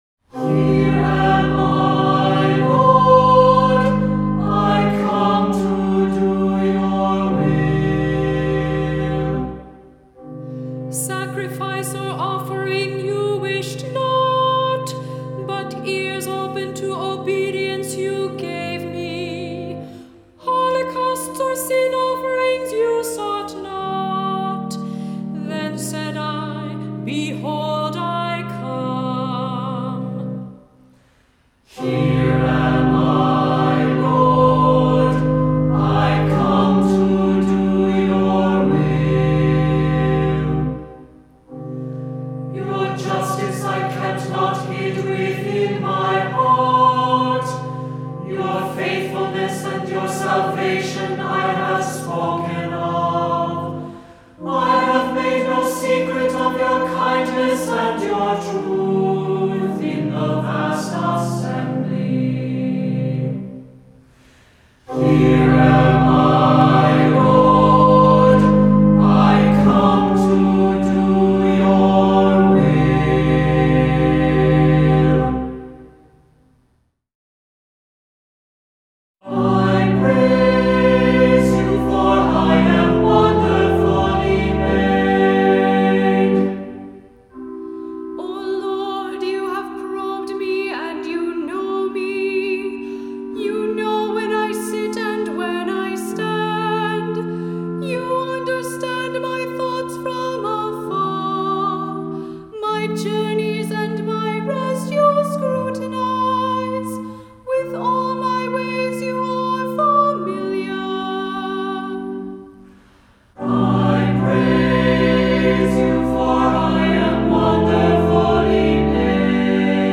Voicing: SATB,Cantor,Assembly